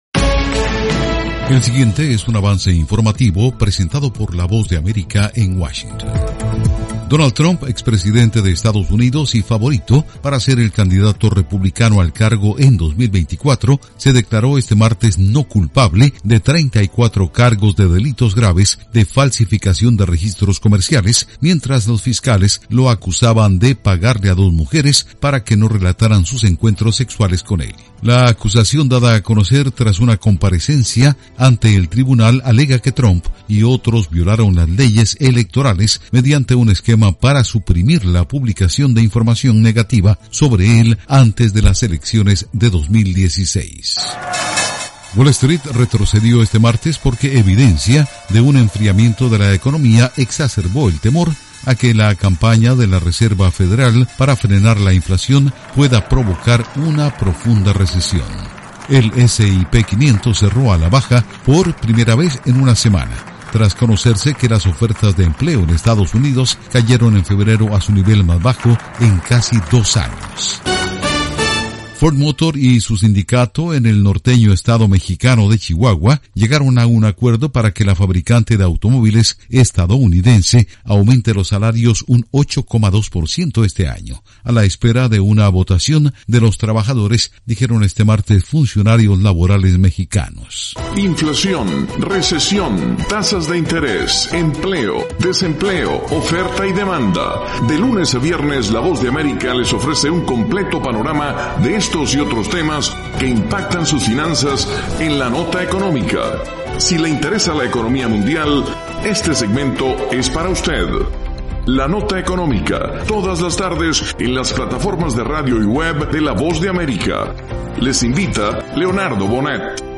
Avance Informativo 6:00 PM
El siguiente es un avance informativo presentado por la Voz de América en Washington.